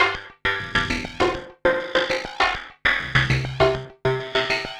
tx_synth_100_phraze_EGAC1.wav